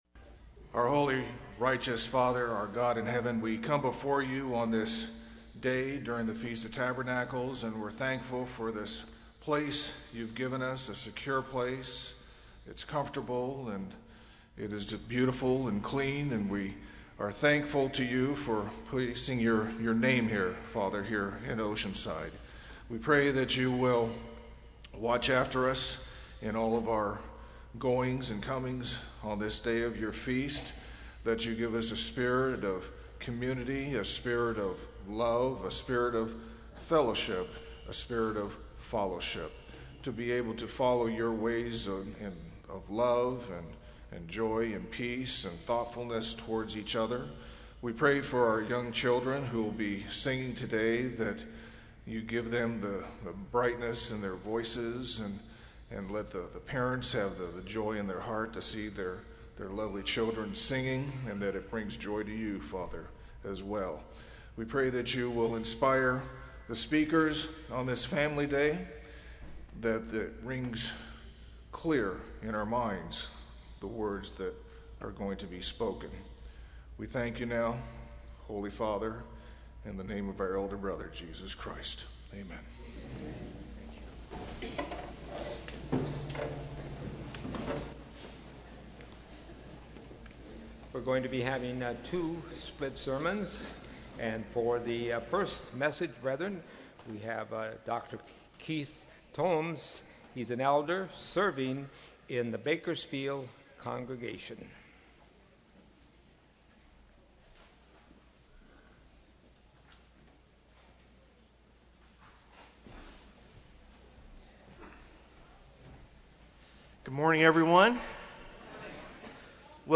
This sermon was given at the Oceanside, California 2015 Feast site.